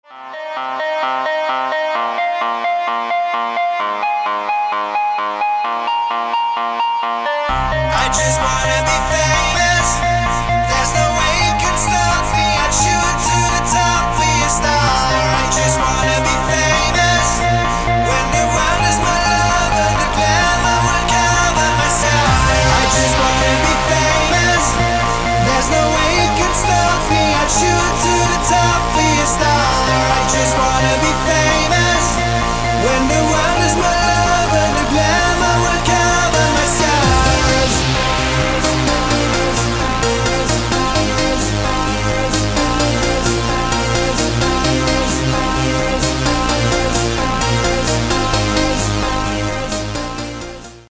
• Качество: 256, Stereo
мужской вокал
Electronic
EDM
электронная музыка
спокойные
клавишные
Trance